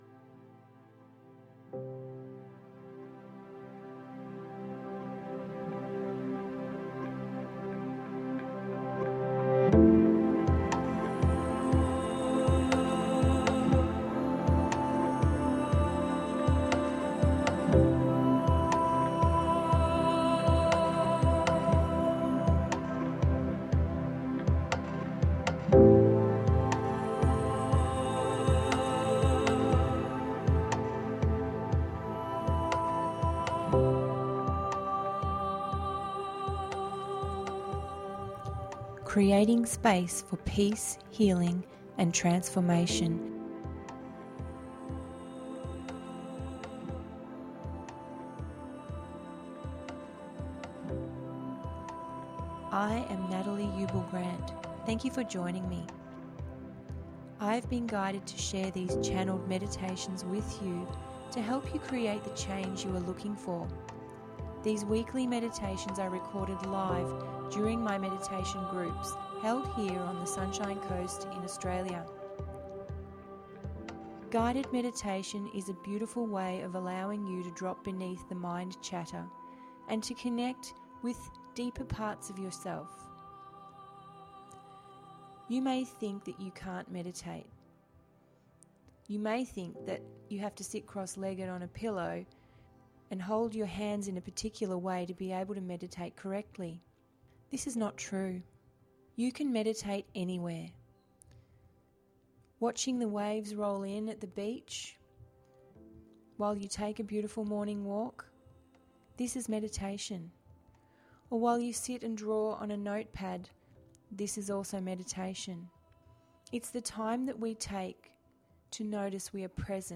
Guided Meditation duration approx. 20 mins